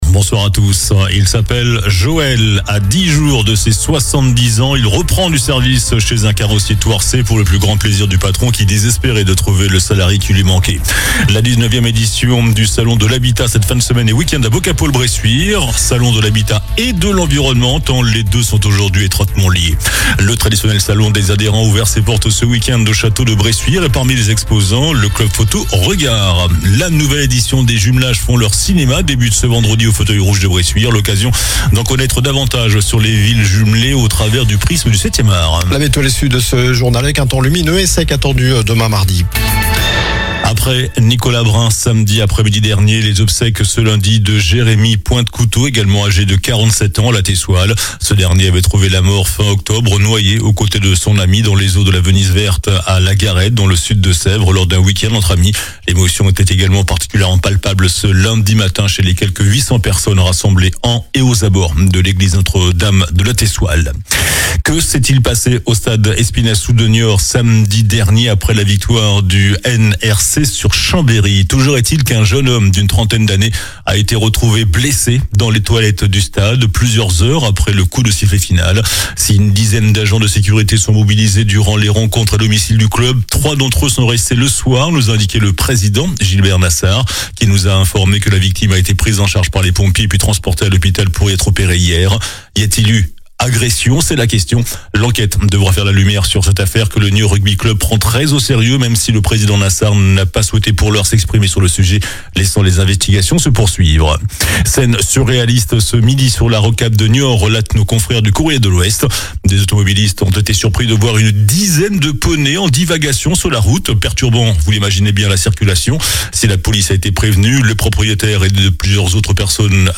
Journal du lundi 10 novembre (soir)
infos locales 10 novembre 2025